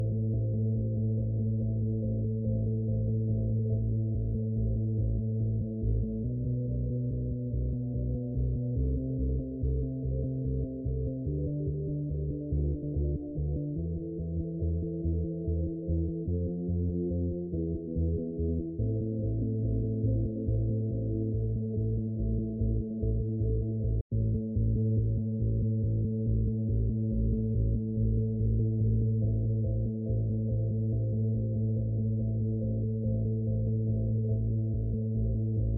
Звук музыки, ощущаемый глухим при максимальной громкости